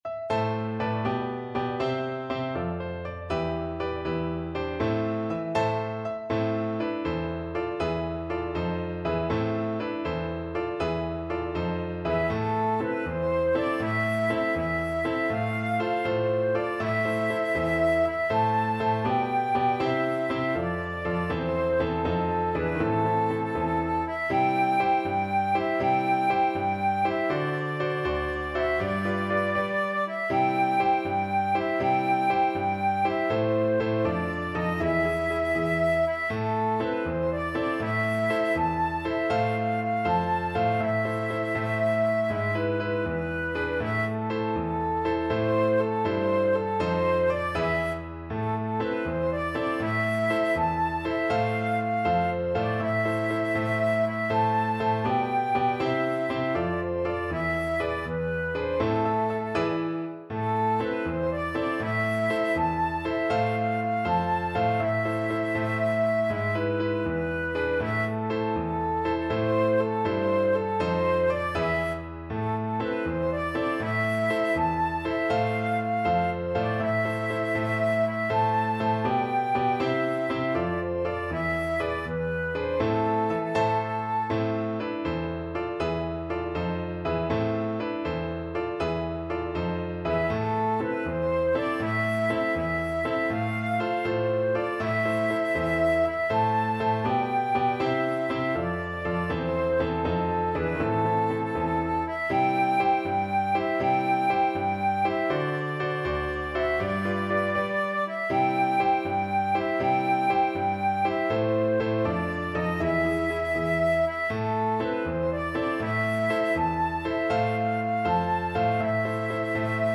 6/8 (View more 6/8 Music)
Brightly, but not too fast
Flute  (View more Easy Flute Music)
Classical (View more Classical Flute Music)